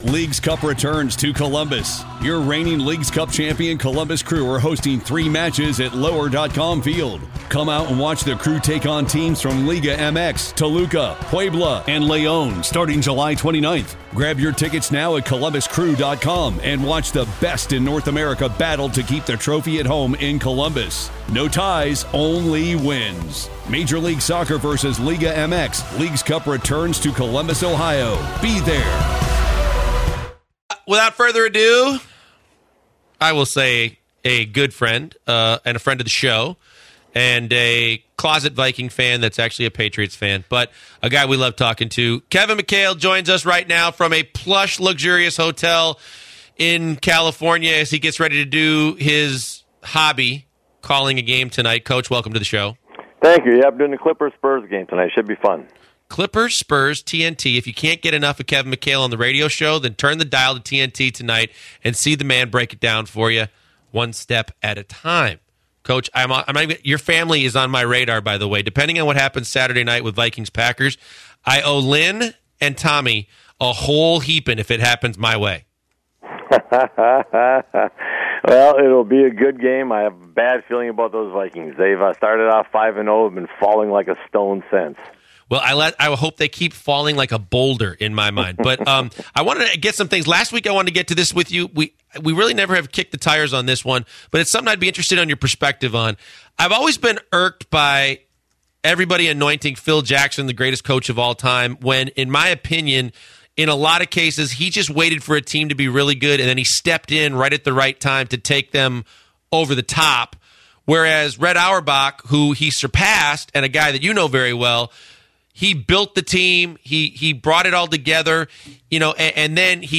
Kevin McHale Interview